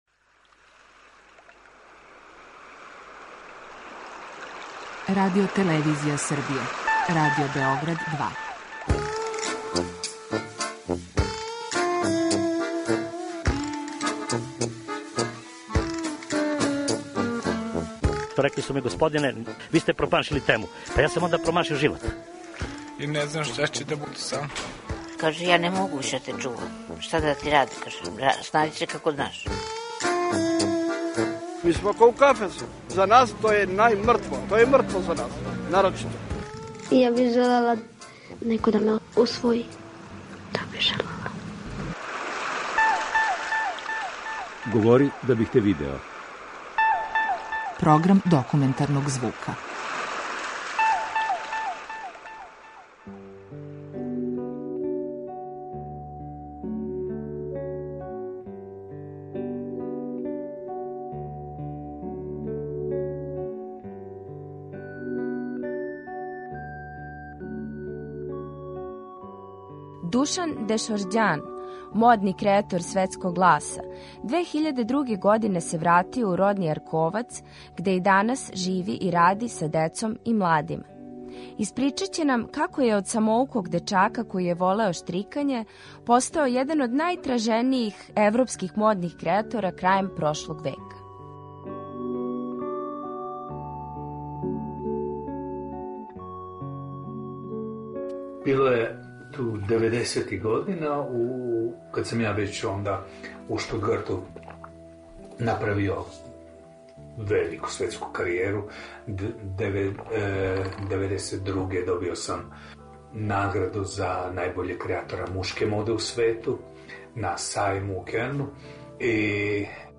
Документарни програм
преузми : 10.81 MB Говори да бих те видео Autor: Група аутора Серија полусатних документарних репортажа, за чији је скупни назив узета позната Сократова изрека: "Говори да бих те видео". Ова оригинална продукција Радио Београда 2 сједињује квалитете актуелног друштвеног ангажмана и култивисане радиофонске обраде.